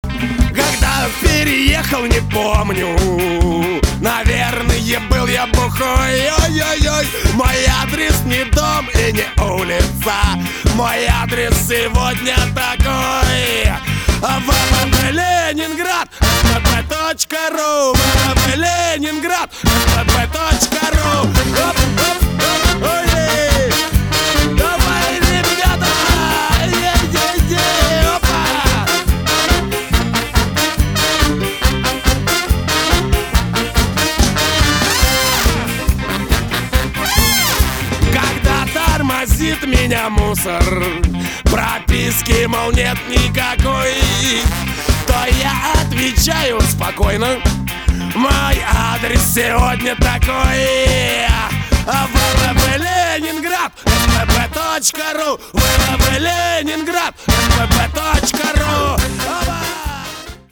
• Качество: 320, Stereo
заводные
русский рок